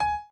b_pianochord_v100l8o6gp.ogg